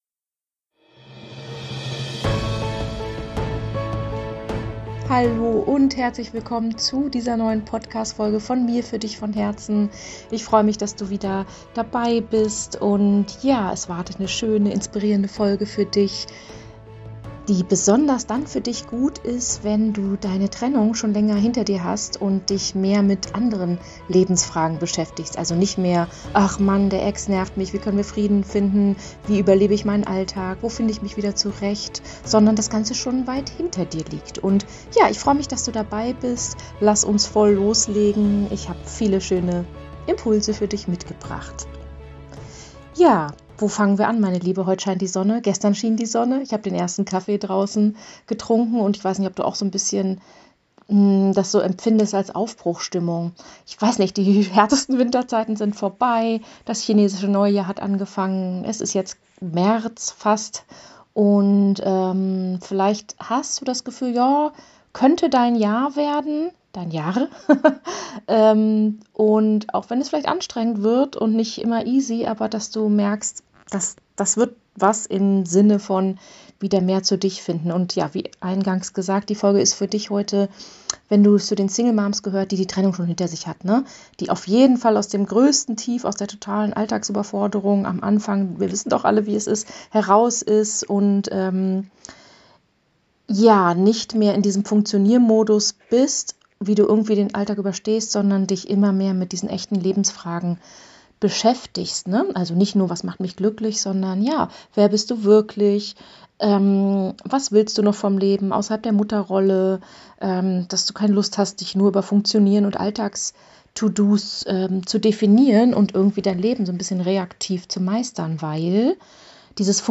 Dich erwartet heute eine frei gesprochene Folge direkt aus meinem Herzen und wenn du dich auch langsam in dieser neuen Lebensphase wiedererkennst, lade ich dich außerdem zu unserem neuen LIVE Webinar zum 1 Jahr Happy Single Mom HOME Community - Geburtstag der HSM HOME Community ein: Was kommt, wenn die Trennung vorbei ist und die Sinnfrage beginnt?